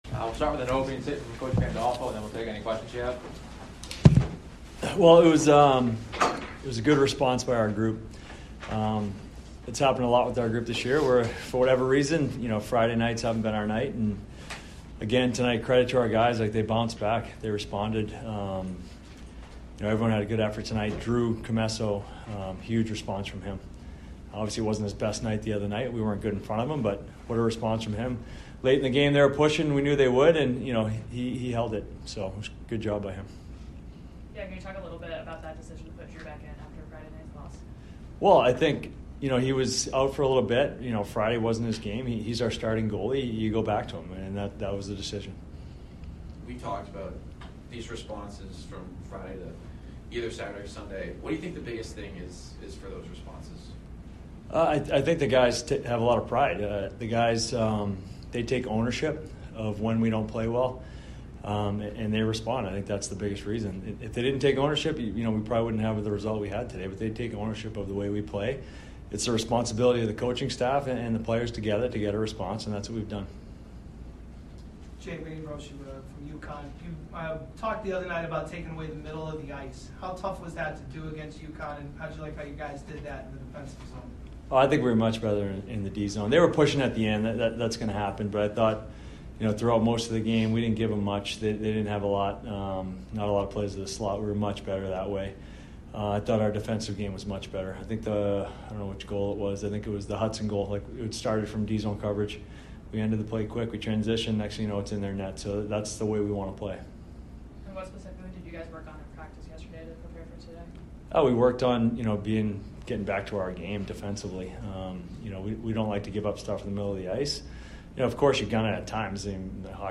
Men's Ice Hockey / UConn Postgame Interview